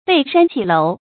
背山起樓 注音： ㄅㄟˋ ㄕㄢ ㄑㄧˇ ㄌㄡˊ 讀音讀法： 意思解釋： 靠山建造樓房。